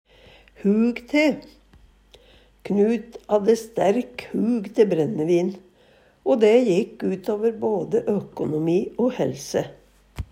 DIALEKTORD PÅ NORMERT NORSK hug te ha før vane, ha lyst på, ha hug på Eksempel på bruk Knut hadde stærk hug te brennevin, o dæ jæ jekk utåver både økonomi o hælse.